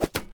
arrow-impact-2.ogg